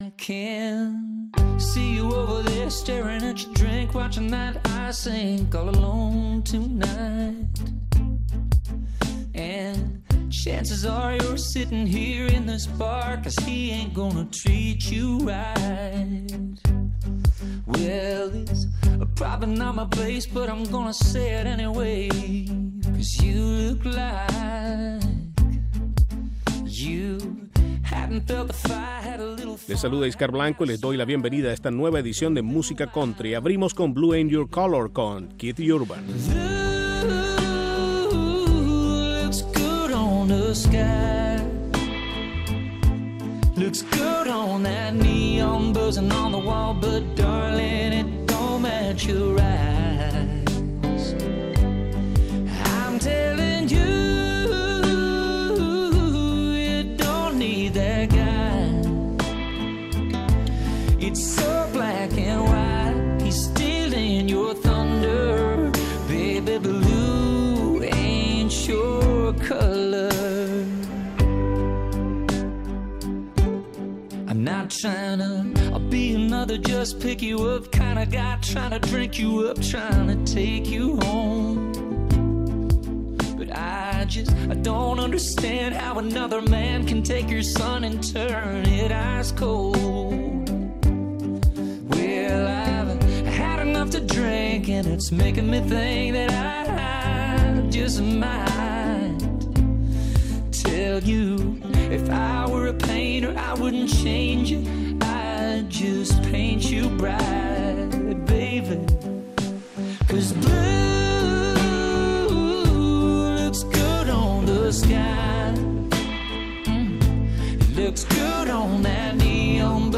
Música Country